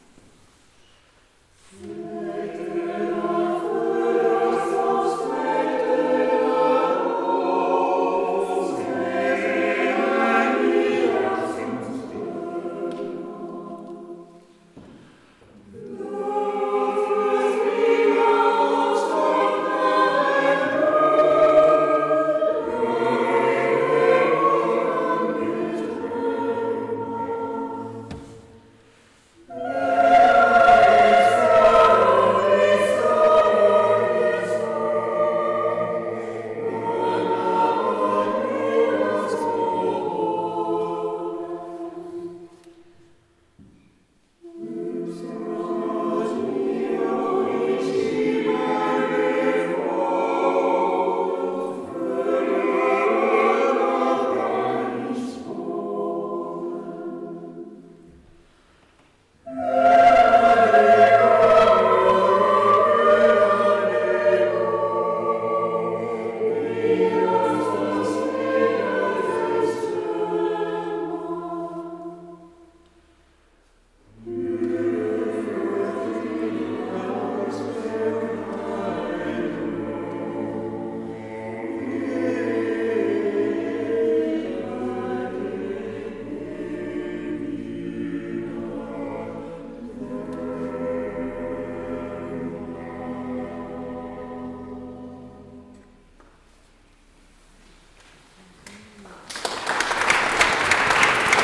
Névache
Rando raquettes et chant choral
Musique chorale du Nord